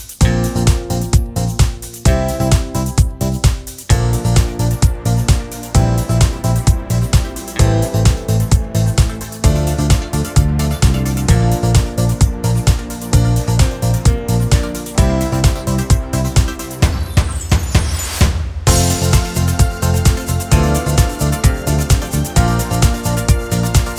no Backing Vocals Irish 3:36 Buy £1.50